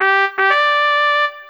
win.wav